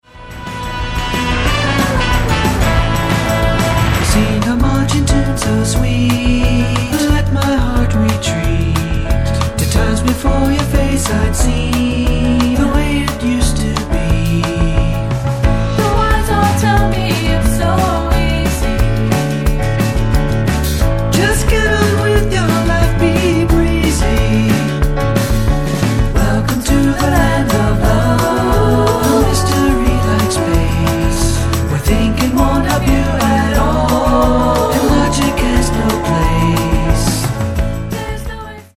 SOFT ROCK/GUITAR POP
ソフトロック、ボサにギターポップまで、全てを取り込み鳴らされる魅惑のポップサウンド。